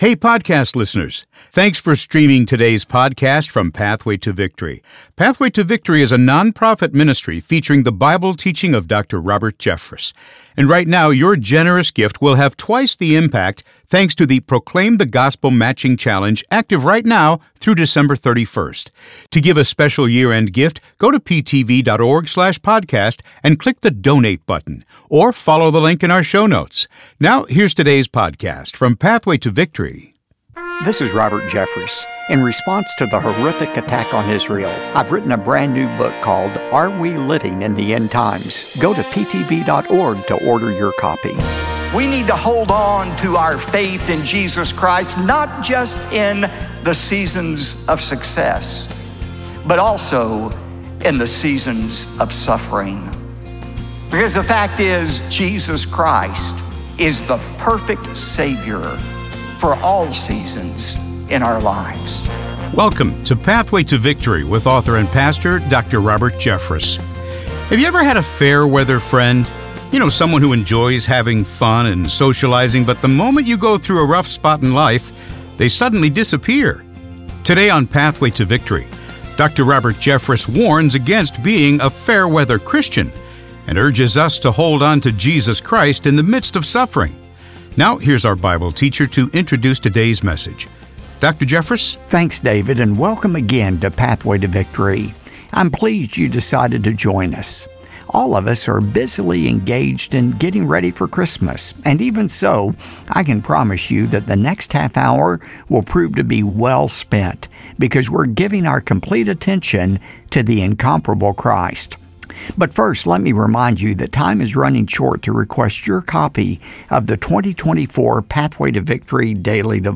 Kerwin Baptist Church Daily Sermon Broadcast